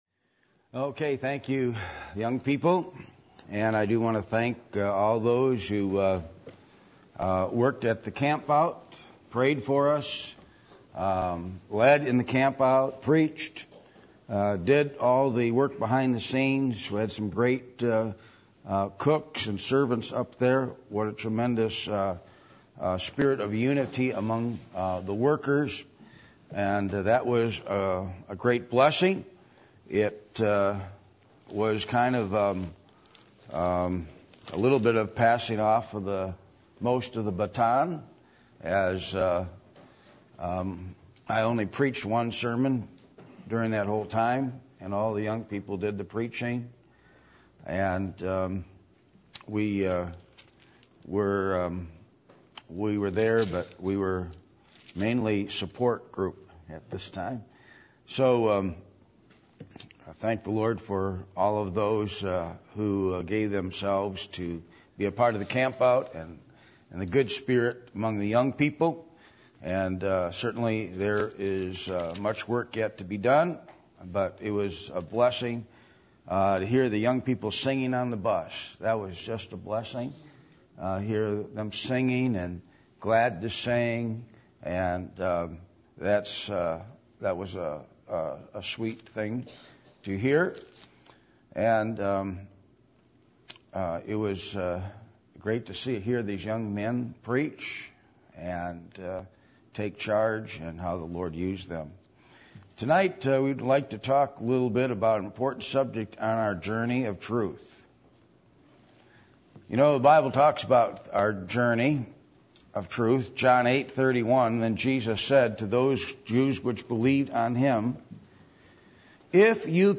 Genesis 2:25 Service Type: Sunday Evening %todo_render% « Who Will Jesus Save?